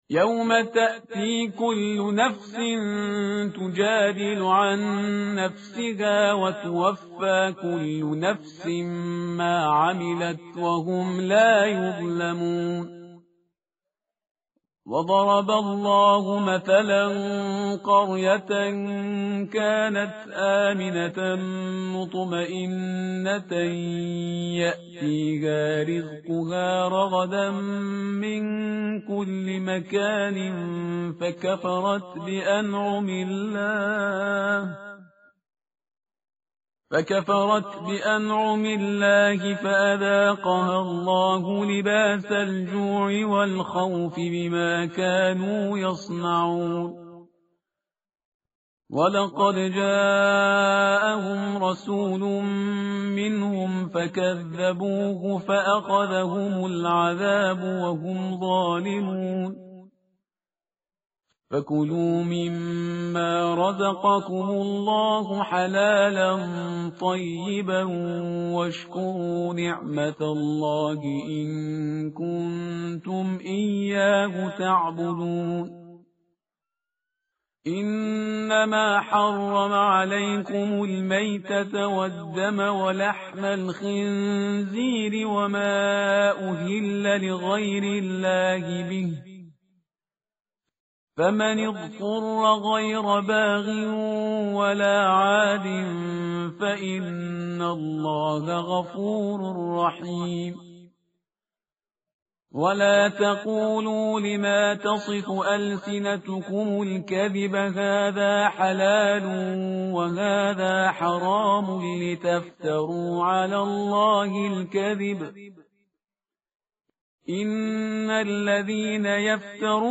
متن قرآن همراه باتلاوت قرآن و ترجمه
tartil_parhizgar_page_280.mp3